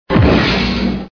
crash.mp3